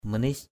/mə-nɪ’s/ (M. manis) manis mn{X [A, 387] 1.
manis.mp3